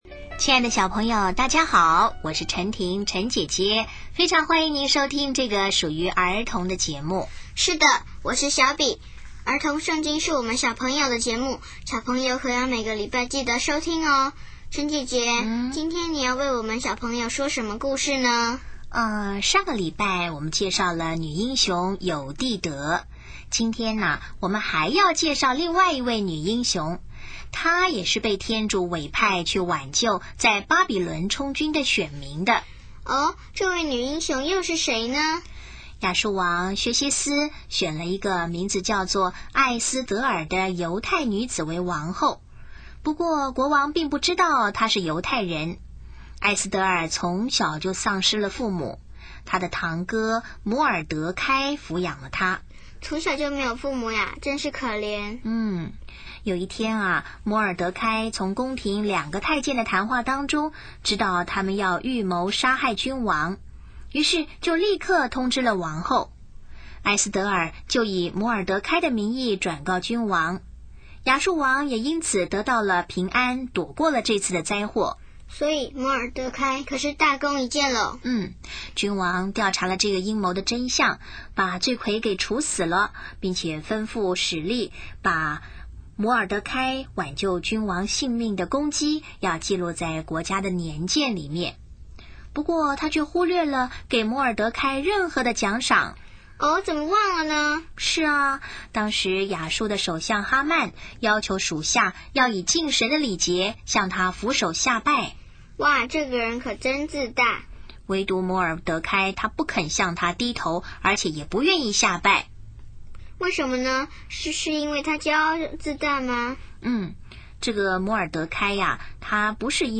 【儿童圣经故事】39|艾斯德尔：天主选派女英雄拯救巴比伦充军选民